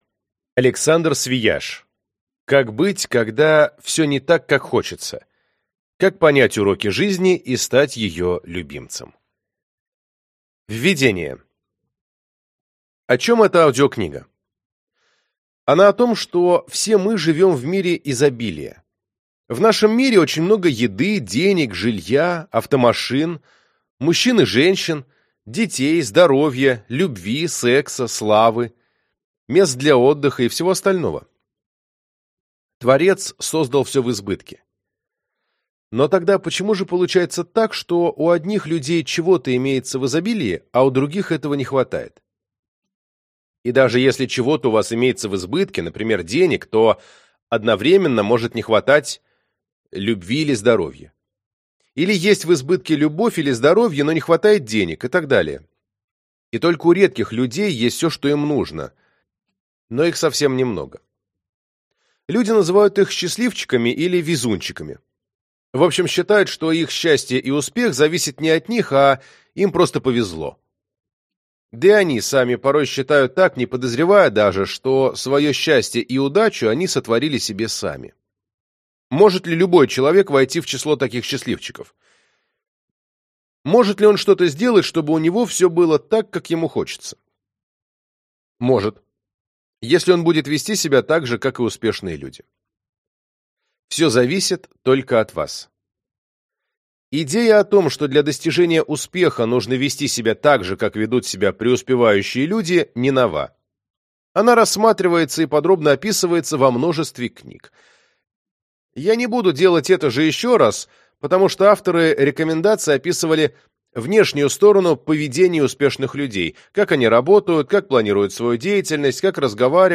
Аудиокнига Как быть, когда все не так, как хочется | Библиотека аудиокниг